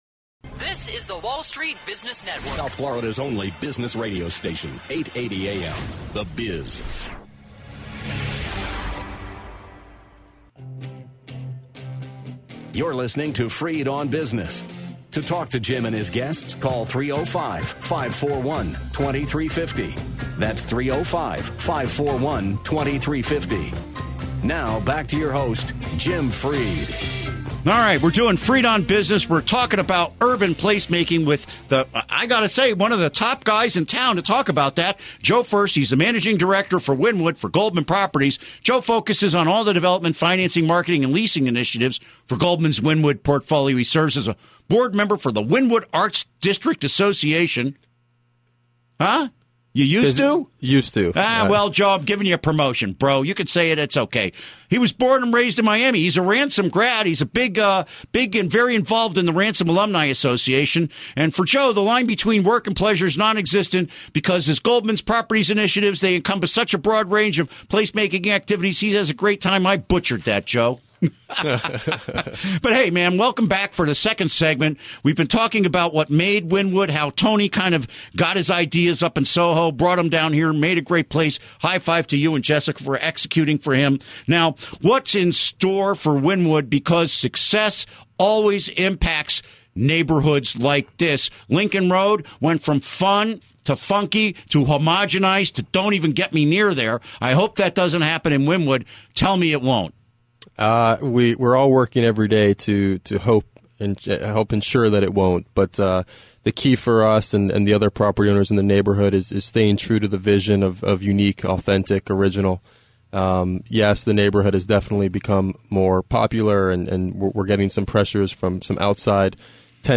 Interview Segment Episode 254: 03-06-14 (To download Part 1, right-click this link and select “Save Link As”.